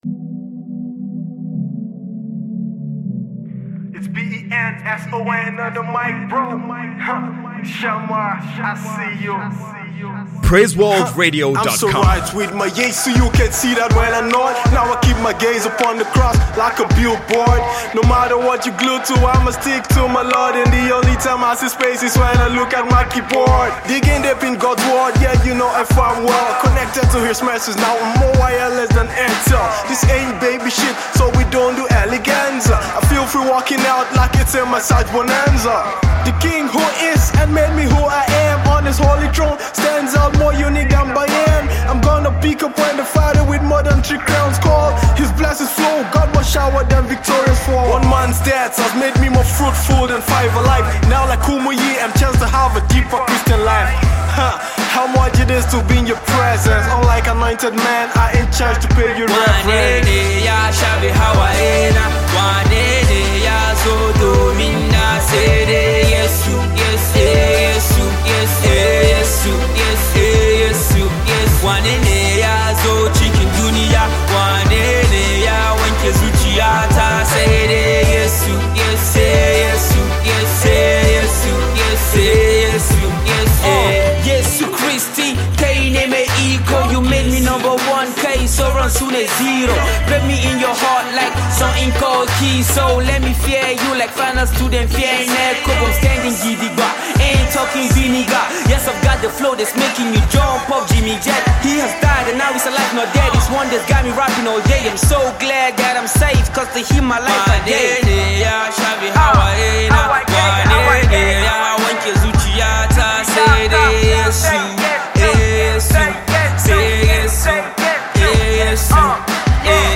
gospel MC